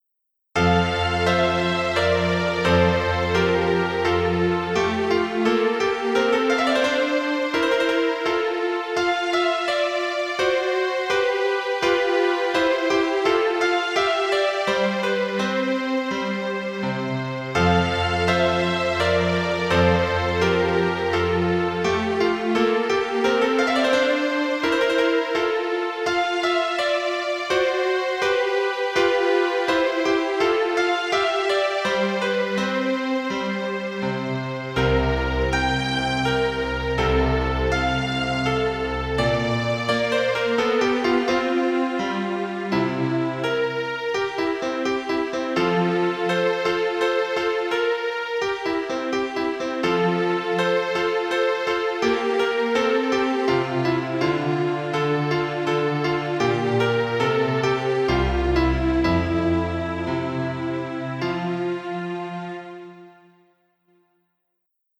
変ロ長調で、アンダンテ（行進曲）という曲名がついています。